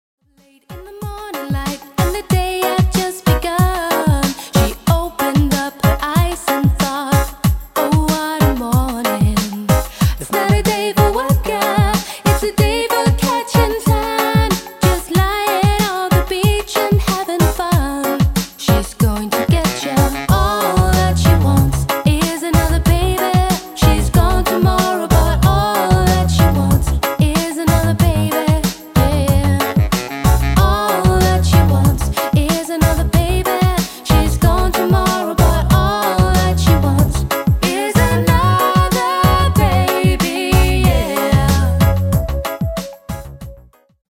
Dansemusik for alle aldre.
• Coverband